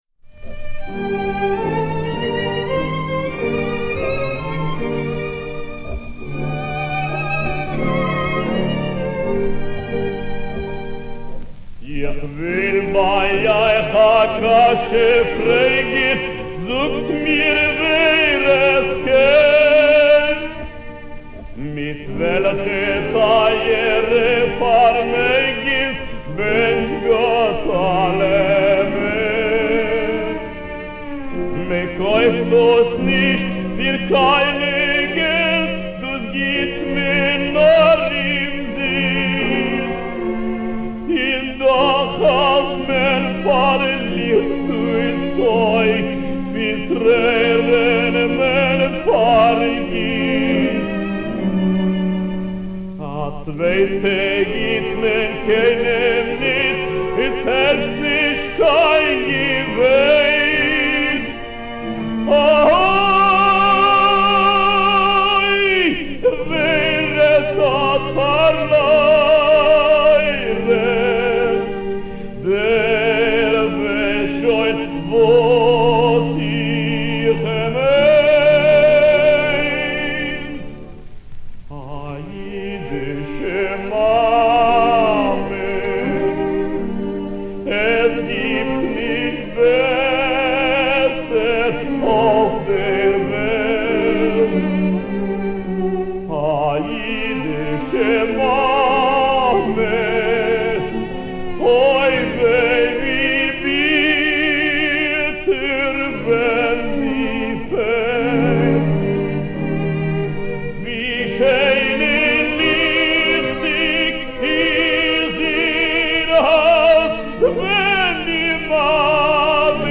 Una gran voz en el arte del canto